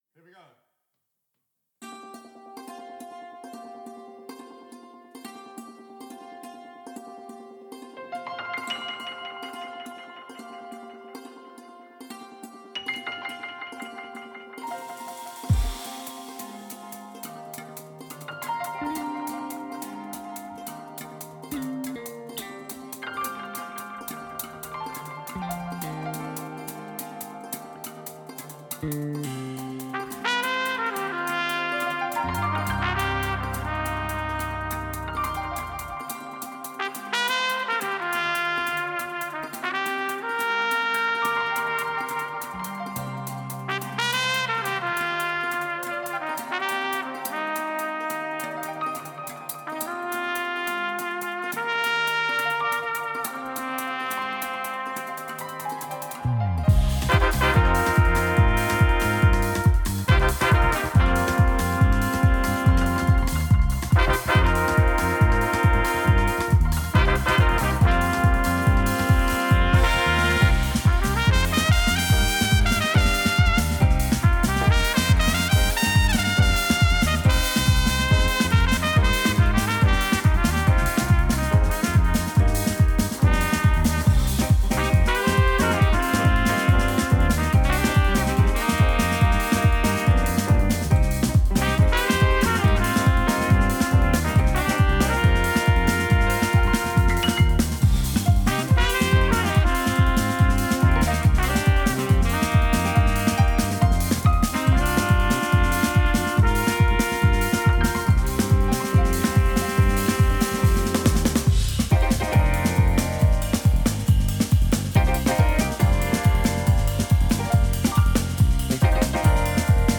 jazz artist
local indie act
singer songwriter
There’s something for everyone with a diverse range of styles packed into a solid thirteen minutes.